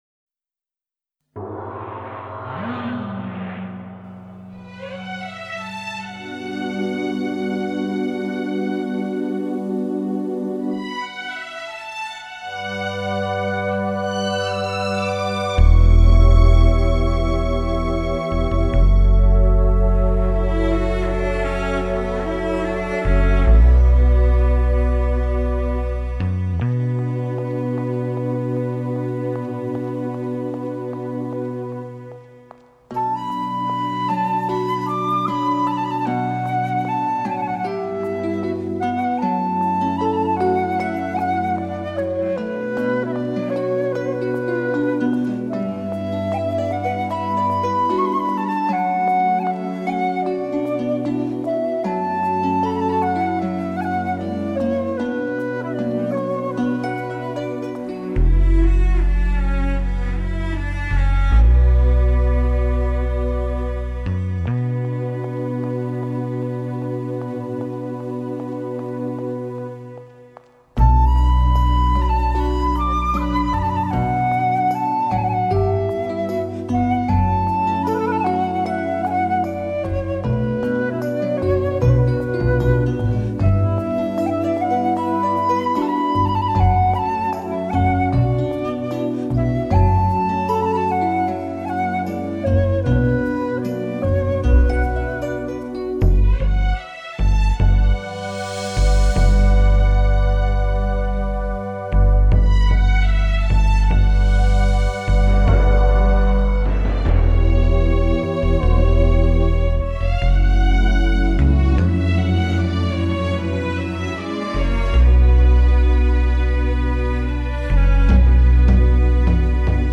0017-牧羊姑娘－箫.mp3